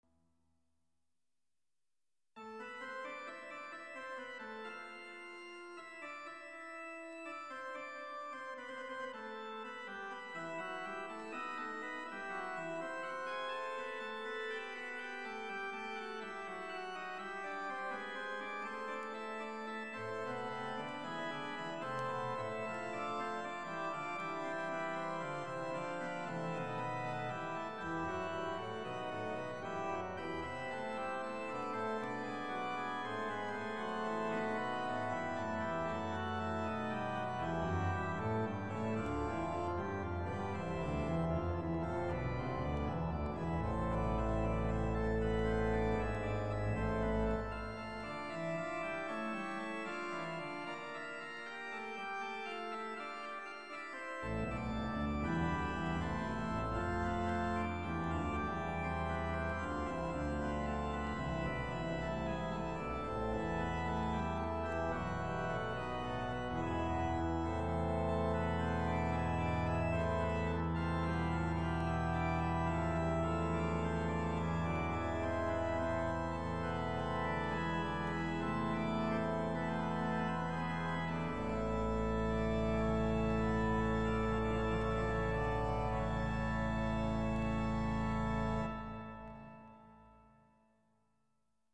Jak si doma postavit kostelní varhany
Reproduktory jsou umístěny vysoko, téměř u stropu na opačných koncích místnosti a vytváří vcelku dobrý prostorový dojem.
Na zvukové kartě doporučuji si nastavit efekt "Concert hall", se kterým získáte pocit kostelního prostoru.